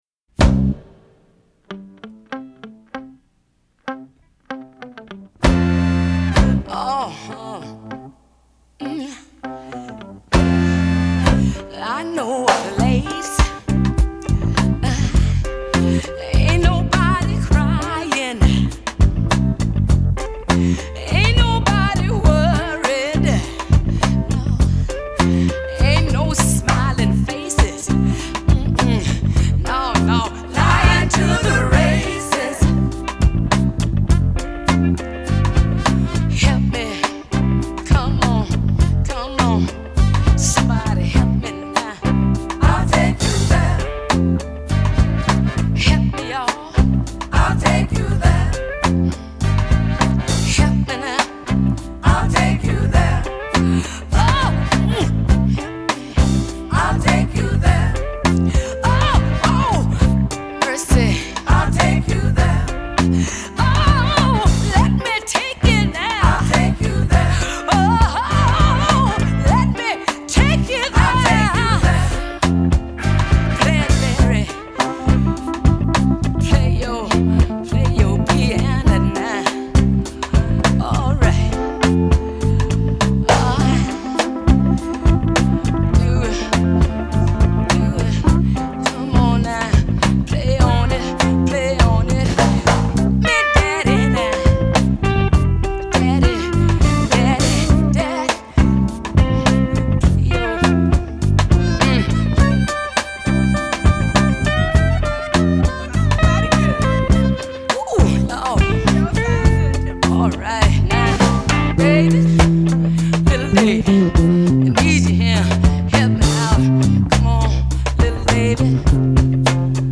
TEMPO : 101
Morceau en C majeur (sensible et 7éme mineure)